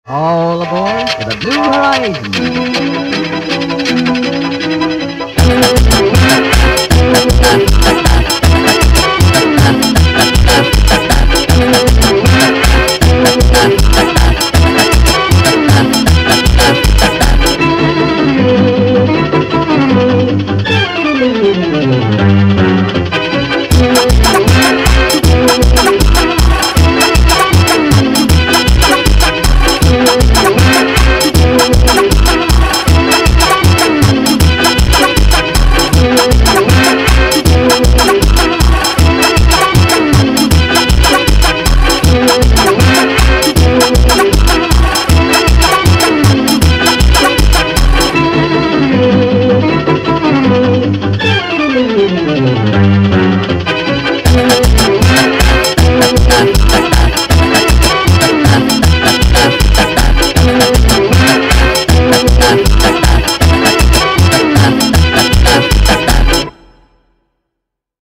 دانلود فانک دارک اینستاگرام مناسب ادیت
فانک